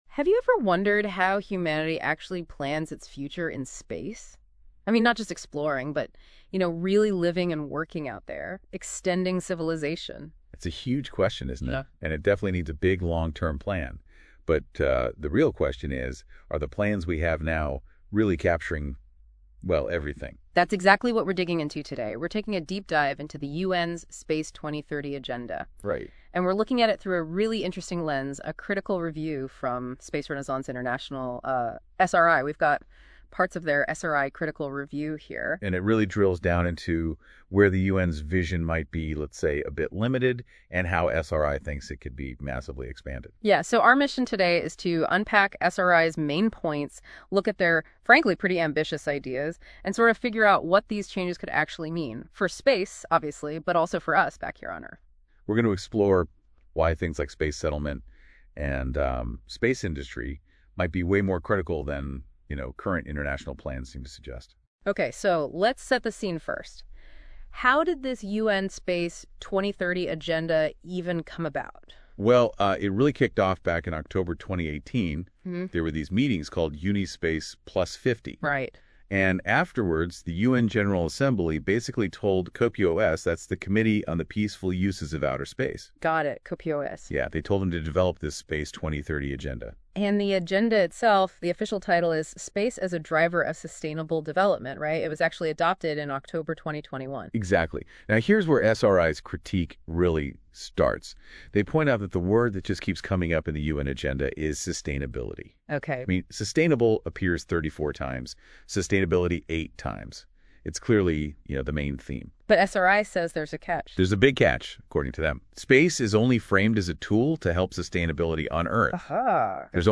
Podcasts are generated using NoteBookLM AI tool (Google)